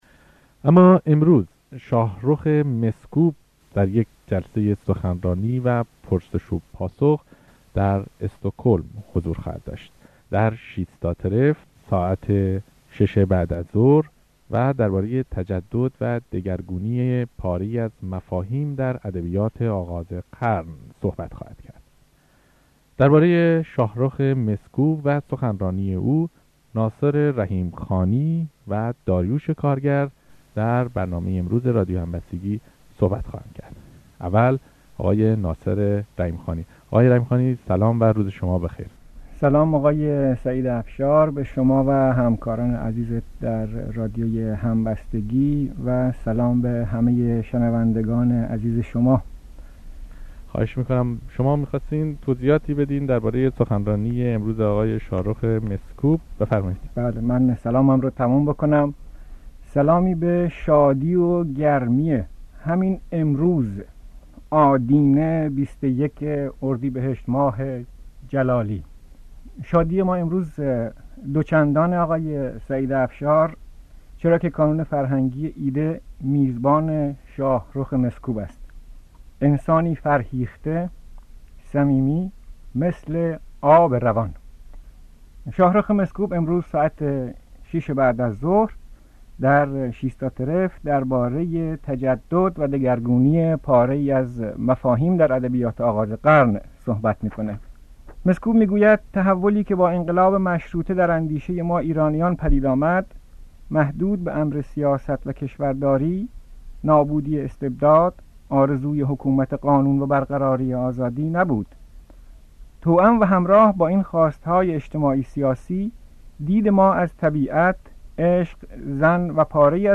فایل صوتی آن برنامهٔ رادیوئی در بارهٔ مسکوب، کارهای مسکوب و جهان ایرانی مسکوب، پیش روی شماست.